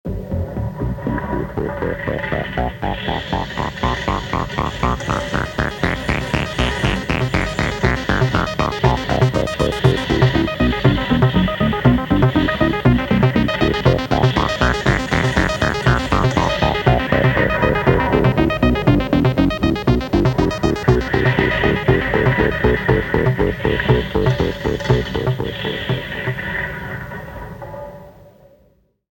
Die Herausforderung für die Start-Stipendiatinnen und Stipendiaten war es, Beethoven´s 5. Synphonie mit dem Modular Synthesizer in Form von Klingeltönen zu zitieren.